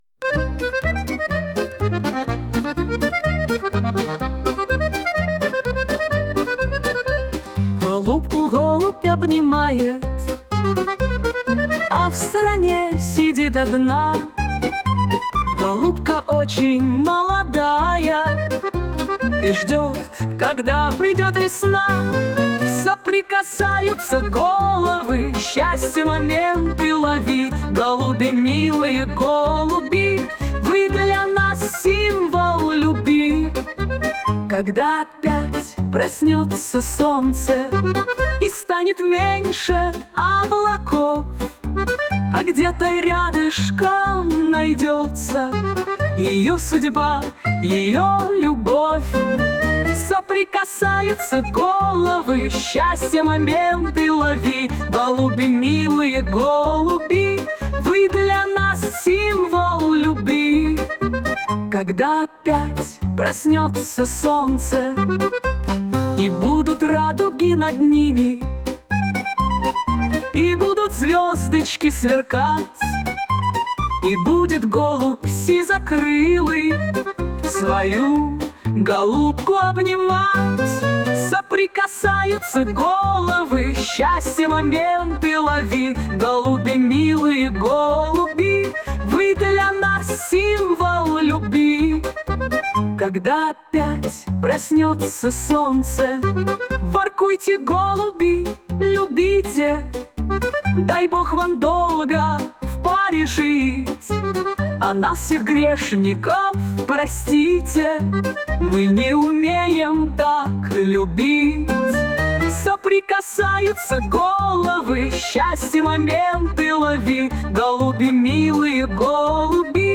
12 12 12 Очень нежная песня, наполнена лаской и любовью!